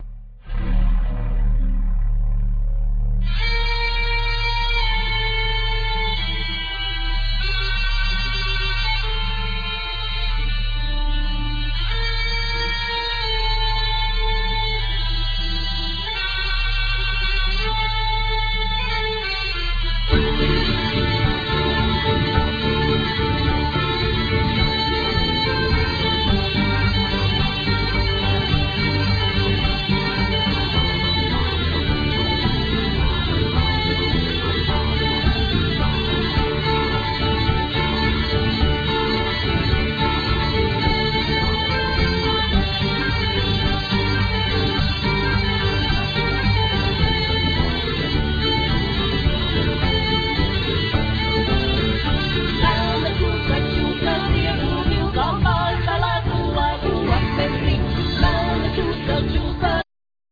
Vocal,Violin,Fiddle,Kokle
Vocal,Giga
Vocal,Kokle,Bagpipe,Acordeon
Guitar
Bungas,Sietins,Bodrans
Programming,Bass,Giga
Mazas bungas